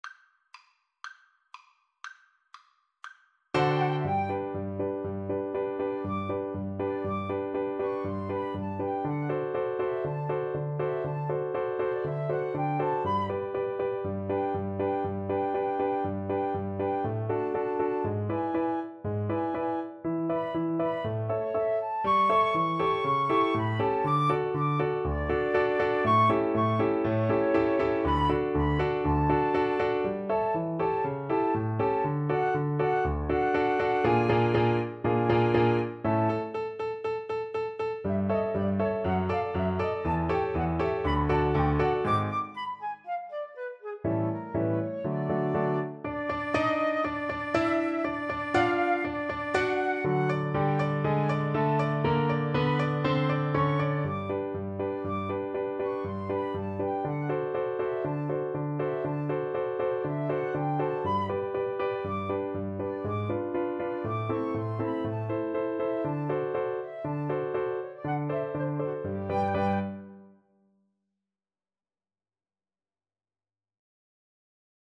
Molto allegro
Classical (View more Classical Flute-Cello Duet Music)